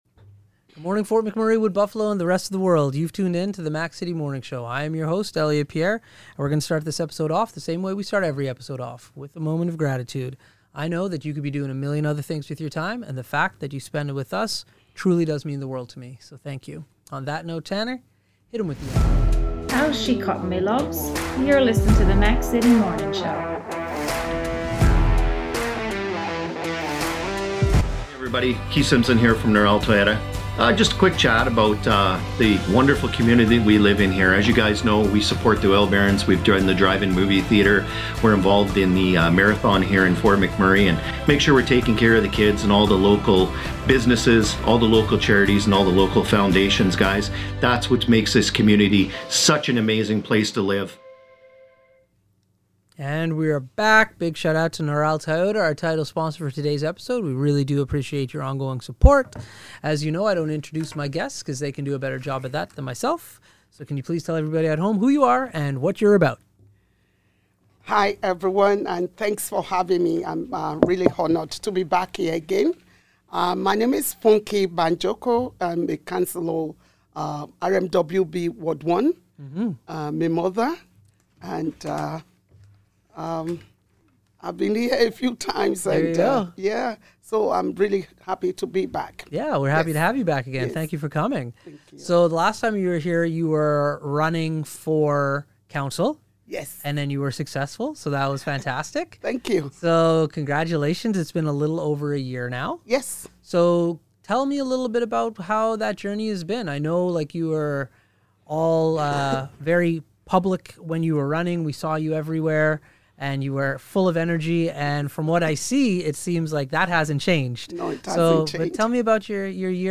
We catch up with Funke Banjoko, a local councilor today!